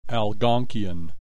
click this icon to hear the preceding term pronounced language group of Indians (Chippewa click this icon to hear the preceding term pronounced, Ojibwa click this icon to hear the preceding term pronounced, and Cree click this icon to hear the preceding term pronounced) living around the Great Lakes of Canada and the United States.